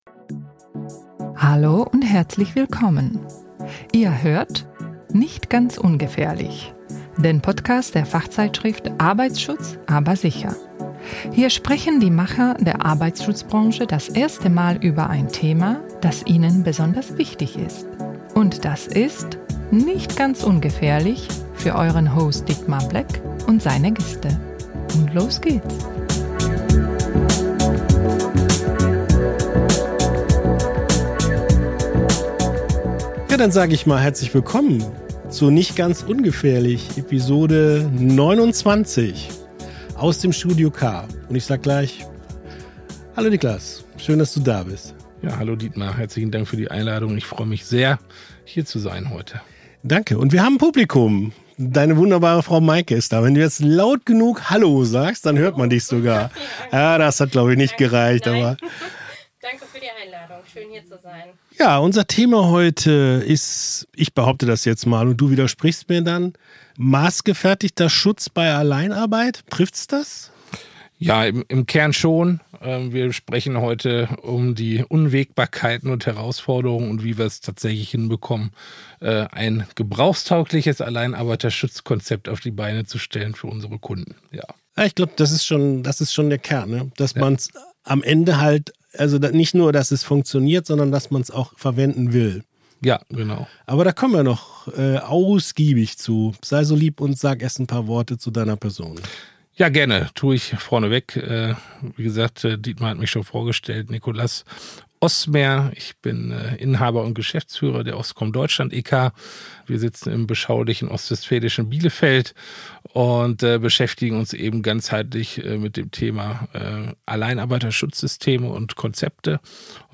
zu Gast im Studio K